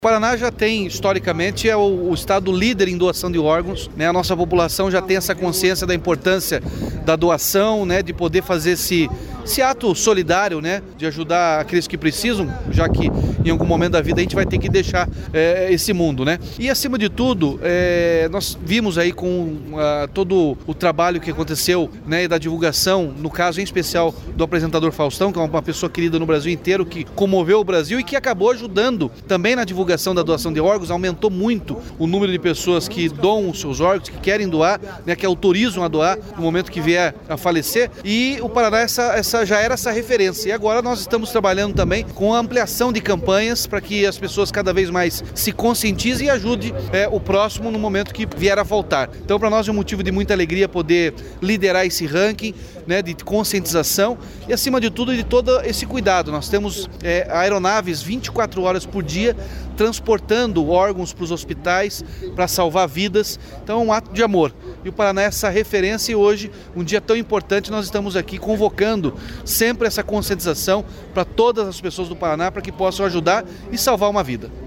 Sonora do governador Ratinho Junior sobre o trabalho do Governo do Estado que faz do Paraná líder nacional na doação de órgãos